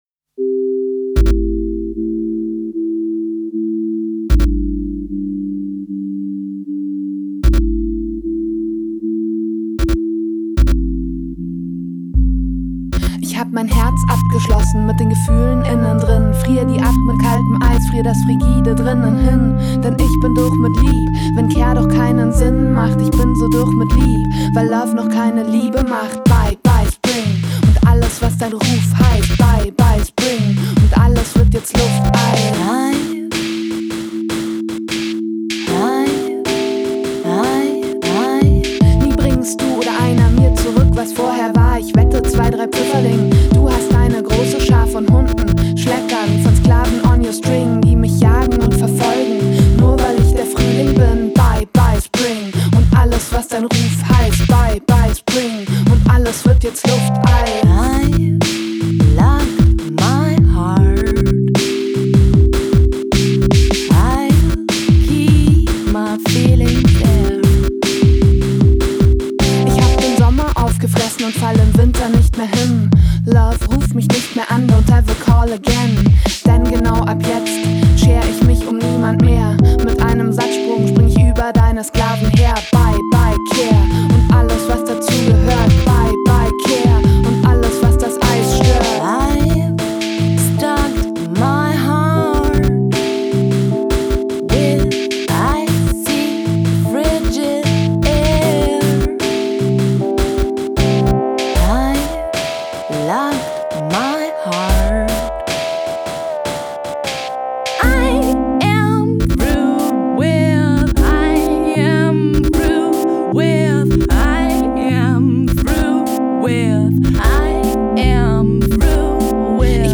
Electronic Music Solo Project since 2003